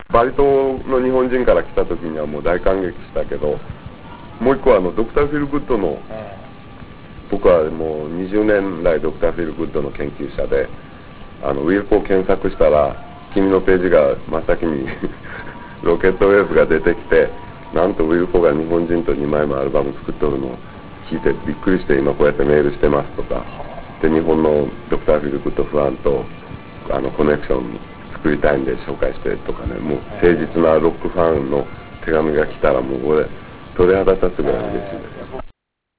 鮎川氏のナマの声を聴けるチャンスです。